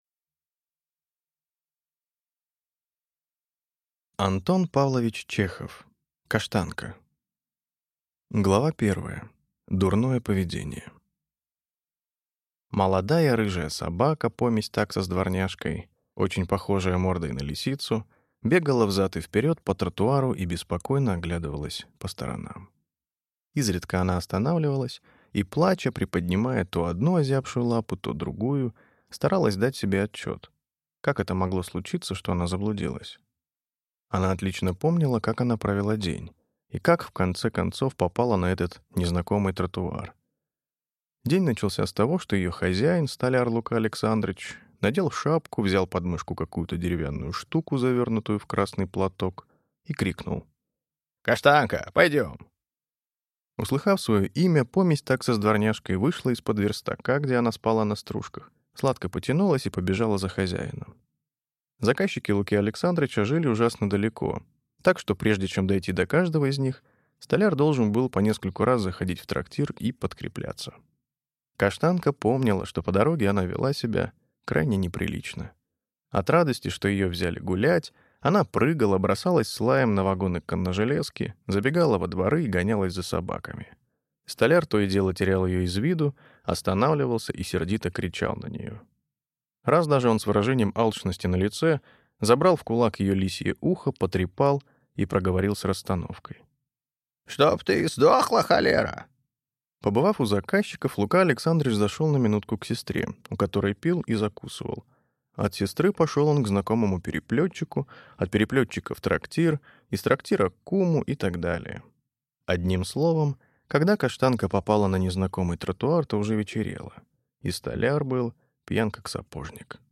Аудиокнига Каштанка | Библиотека аудиокниг